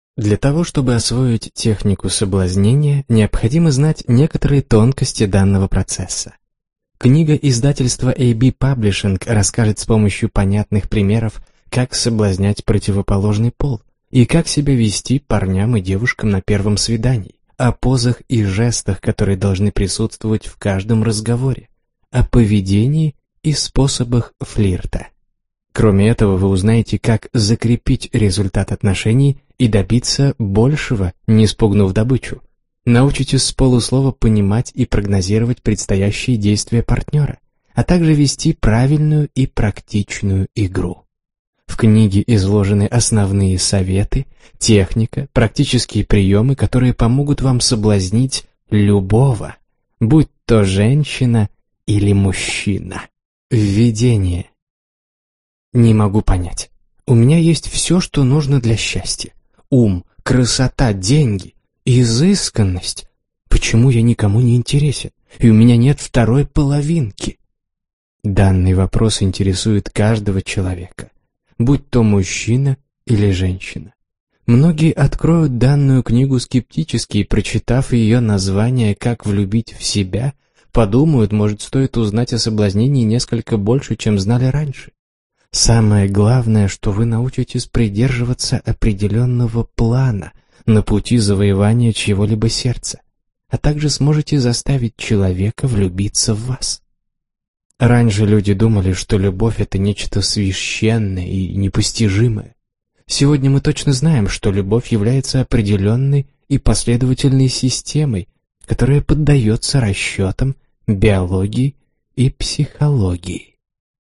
Аудиокнига Как влюбить в себя. Психология соблазнения | Библиотека аудиокниг